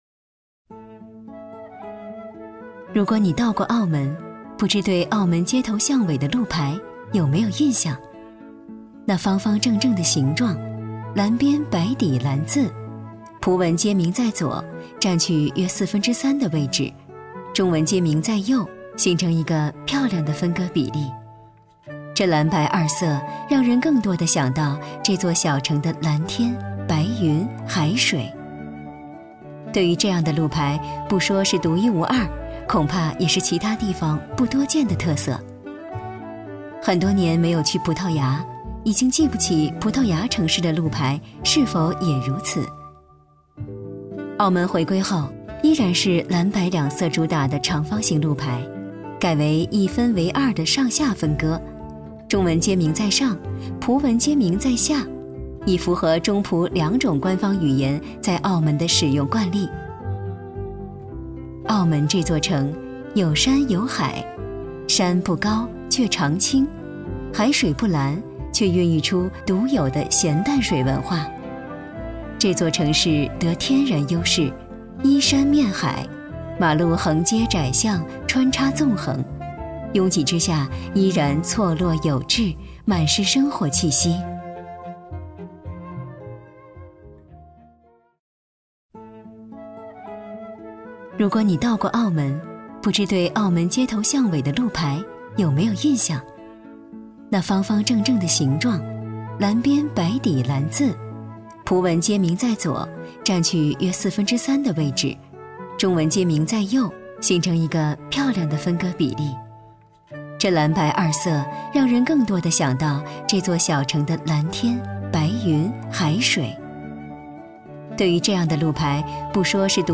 国语青年大气浑厚磁性 、沉稳 、娓娓道来 、亲切甜美 、女专题片 、宣传片 、旅游导览 、60元/分钟女S129 国语 女声 专题片-报告-成熟 大气浑厚磁性|沉稳|娓娓道来|亲切甜美